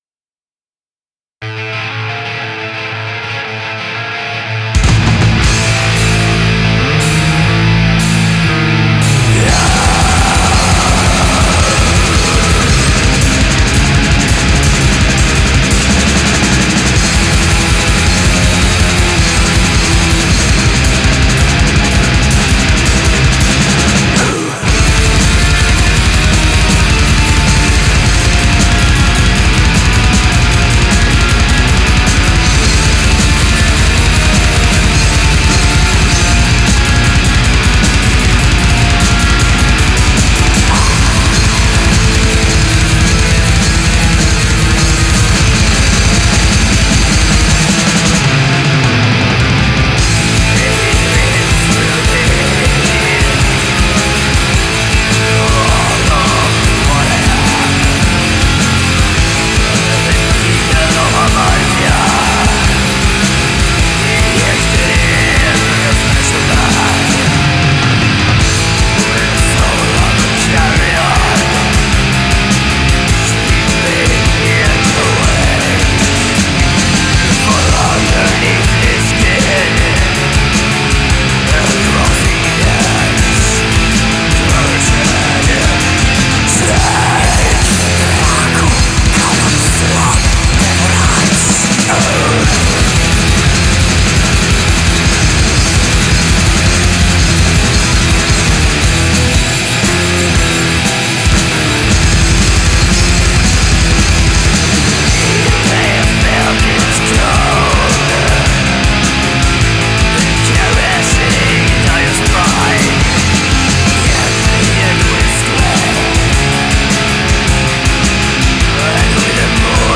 این آلبوم یعنی اهریمن، خشن و بی رحم
Swedish black metal